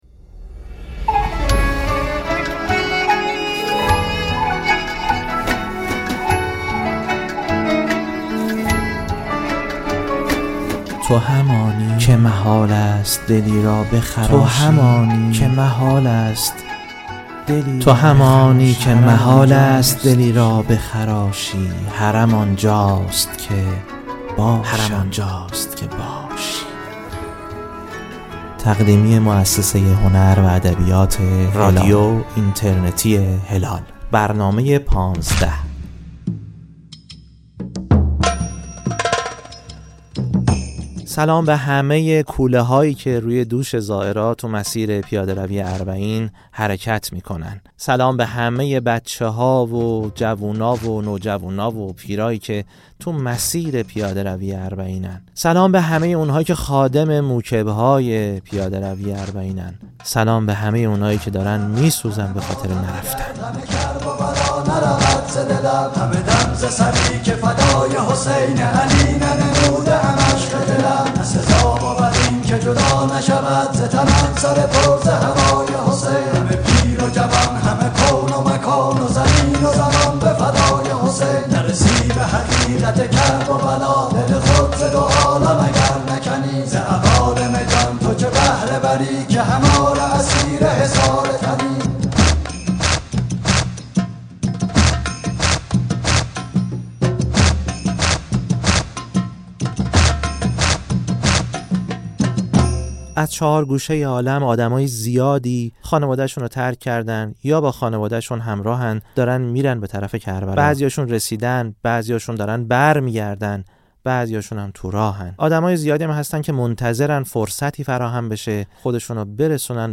در دومین قسمت از مجموعه‌ «پانزده»، با ما همراه شوید در شنیدن واژه‌هایی از شوق، نوحه‌هایی از عمق، و صدایی از معرفت.